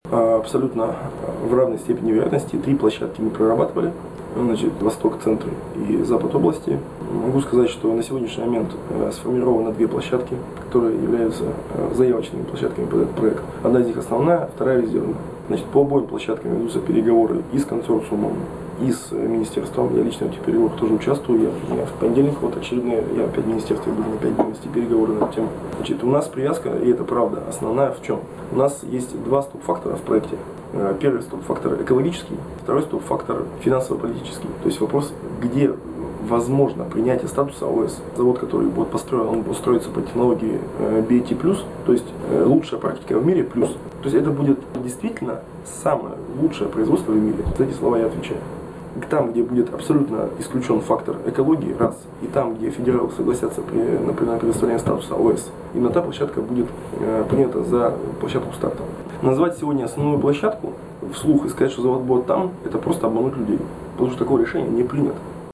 Алексей Кожевников рассказывает о месте строительства комбината